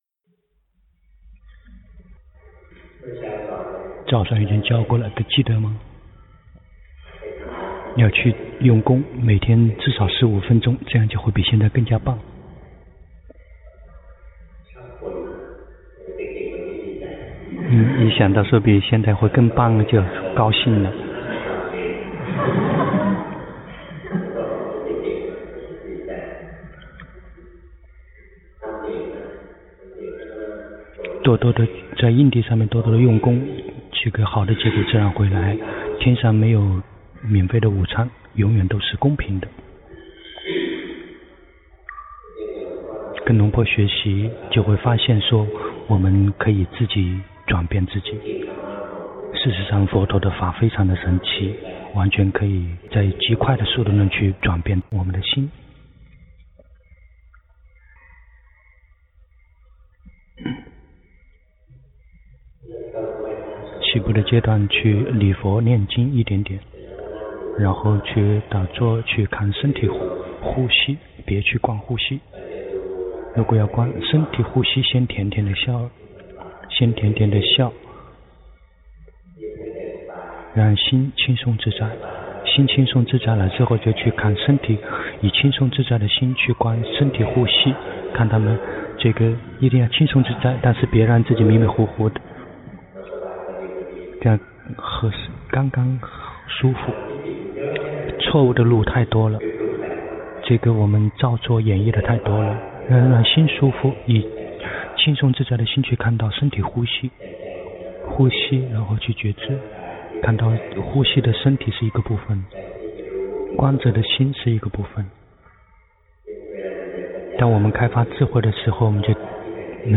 長篇法談｜蘊的分離——隆波帕默尊者
泰國解脫園寺
同聲翻譯
完整開示音頻